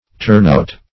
Turn-out \Turn"-out`\ (t[^u]rn"out`), n.; pl. Turn-outs